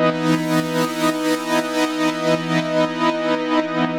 GnS_Pad-MiscA1:8_120-E.wav